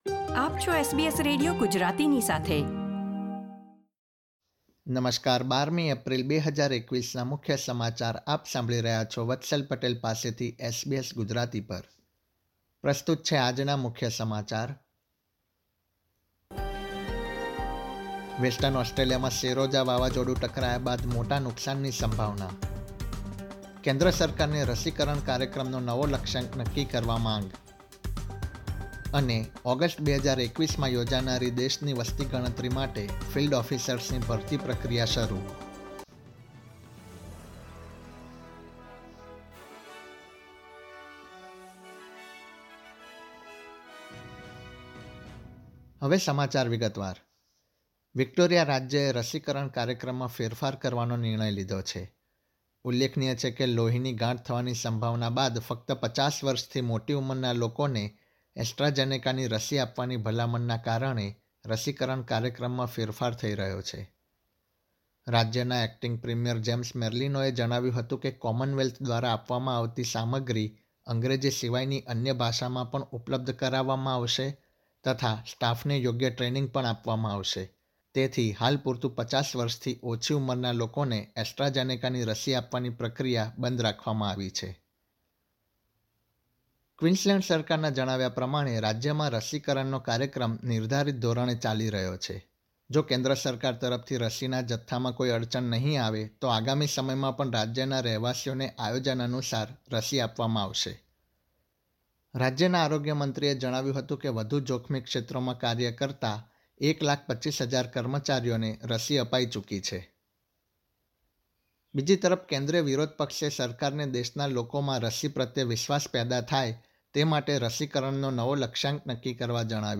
SBS Gujarati News Bulletin 12 April 2021
gujarati_1204_newsbulletin.mp3